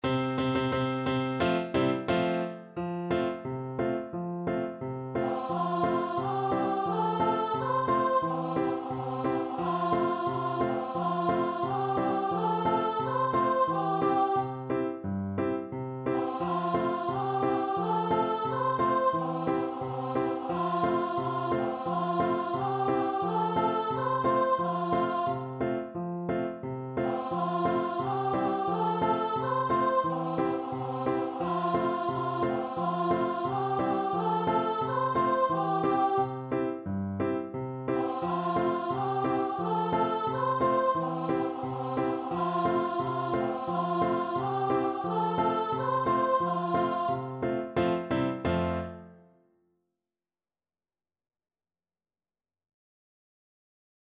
Voice
F major (Sounding Pitch) (View more F major Music for Voice )
Steady march =c.88
2/4 (View more 2/4 Music)
C5-C6
Traditional (View more Traditional Voice Music)
Swiss